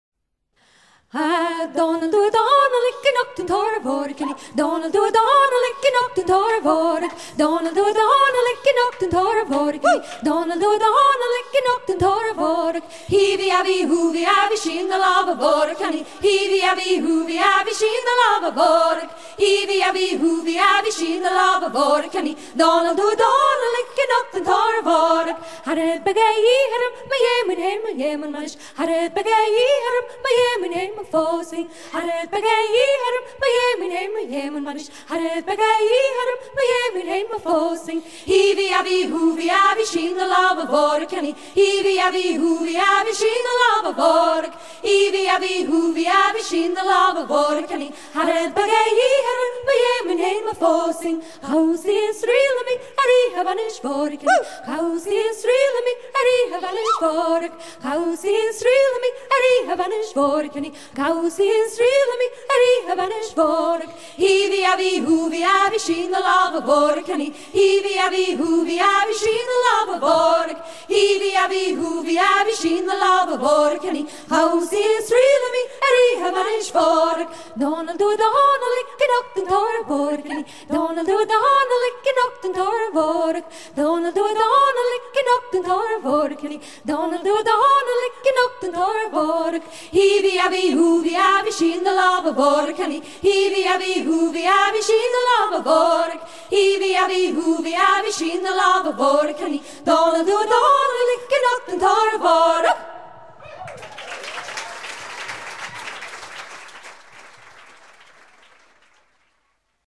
danse d'amour de l'île de Terceira
Pièce musicale éditée